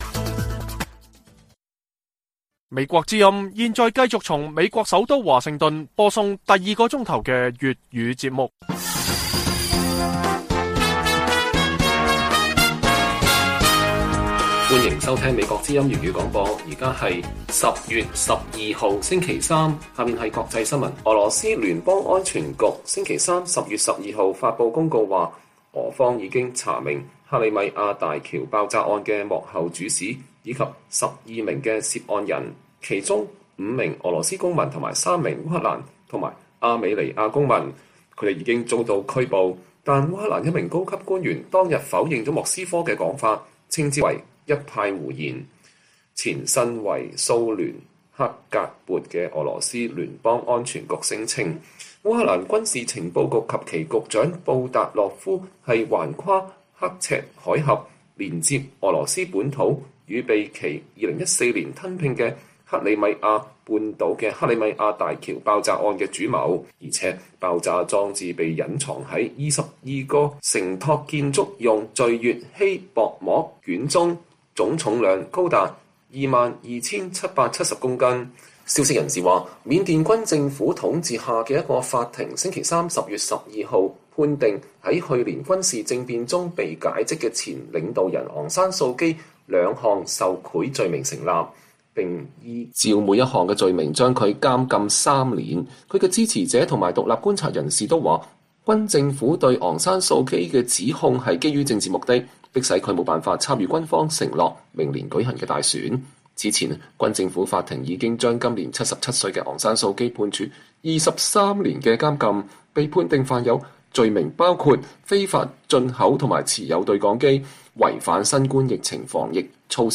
粵語新聞 晚上10-11點： 莫斯科稱拘捕克里米亞大橋爆炸案嫌疑人，烏克蘭指責是“一派胡言”